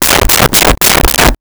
Knocking On Door With Knuckles 5 Times
Knocking on Door with Knuckles 5 times.wav